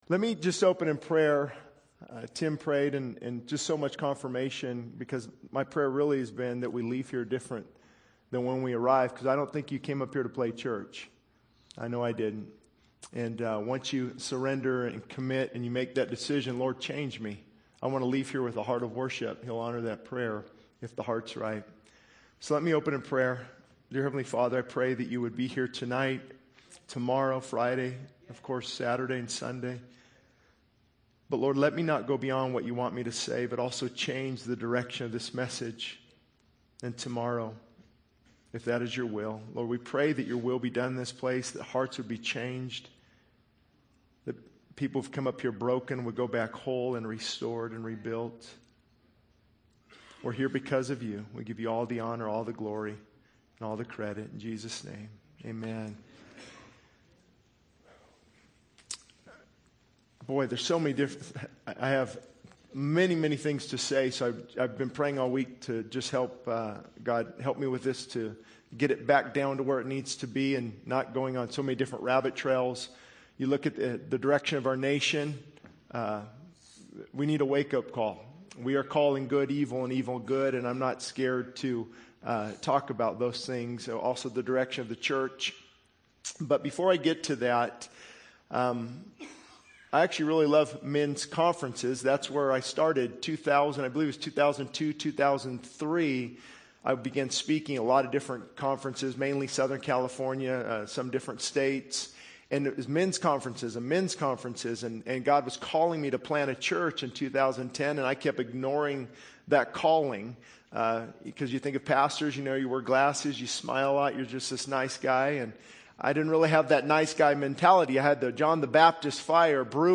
This sermon emphasizes the importance of humility, prayer, seeking God's face, and repentance. It addresses the need for men to lead biblically in their homes, highlighting the destructive nature of pride and the call to die to self. The speaker urges a return to a fervent prayer life and seeking God with a passionate pursuit, acknowledging the critical role of humility in restoring marriages and families.